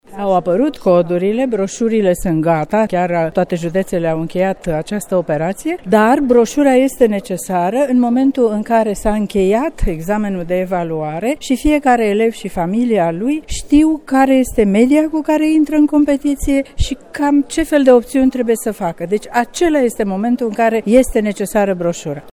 Ecaterina Andronescu a vizitat Brașovul cu ocazia împlinirii a 150 de ani de existență a Colegiului Dr. Ioan Meșotă. Cu acest prilej, ministrul Educației a declarat că broșurile de admitere în licee au fost finalizate: